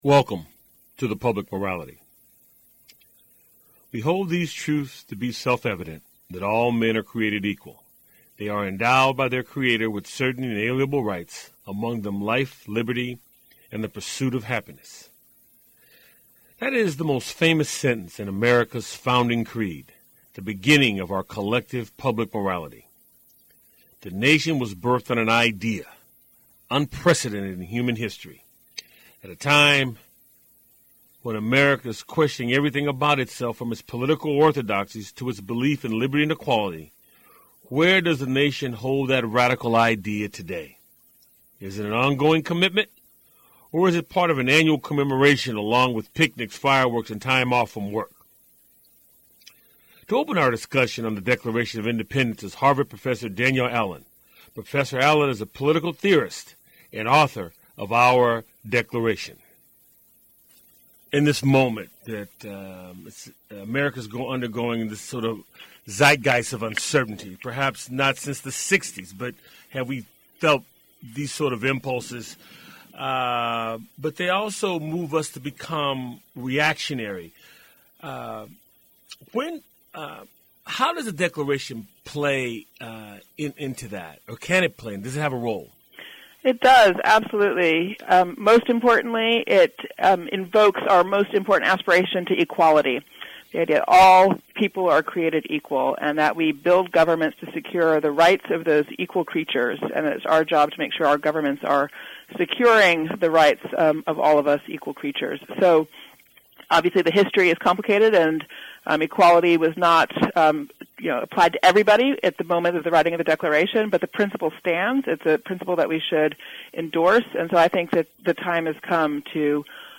It's a weekly conversation with guest scholars, artists, activists, scientists, philosophers, and newsmakers who focus on the Declaration of Independence, the Constitution and the Emancipation Proclamation as its backdrop for dialogue on issues important to our lives. The show airs on 90.5FM WSNC and through our Website streaming Tuesdays at 7:00p.